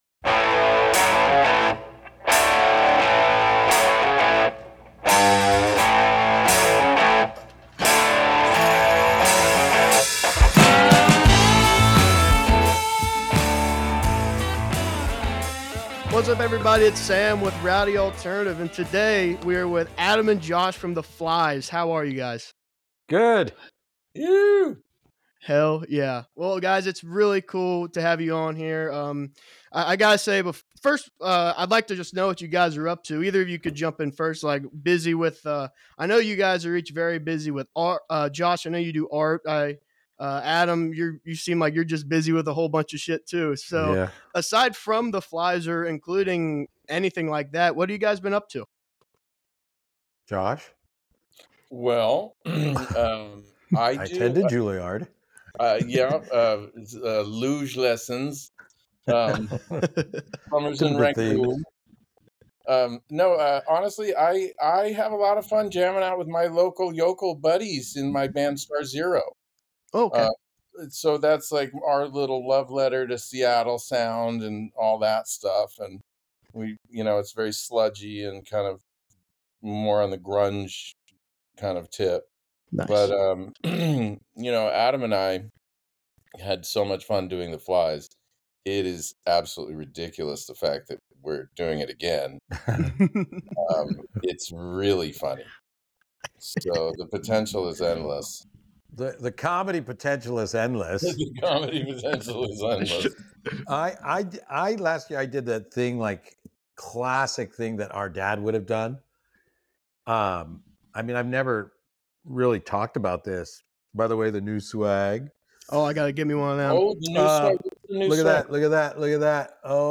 The conversation touches on the challenges and excitement of reforming the band, their diverse artistic pursuits, and the timeless quality of their music. They also preview their participation in the upcoming Rhythm and Resin Festival, a celebration of surf culture and local talent.